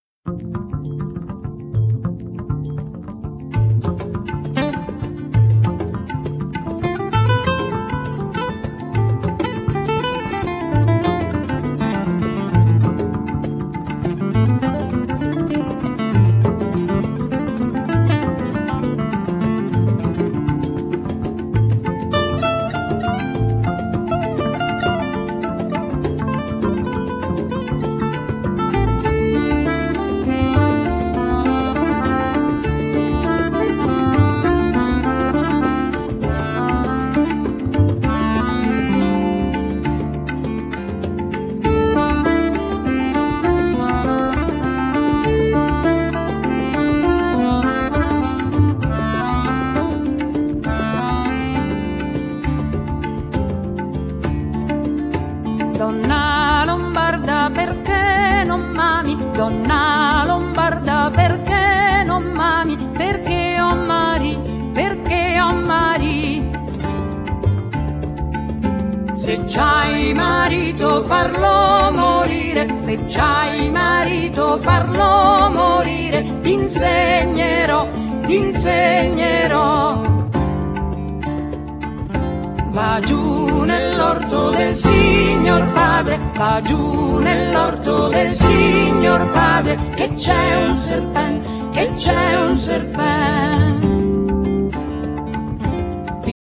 Genere : Pop Folk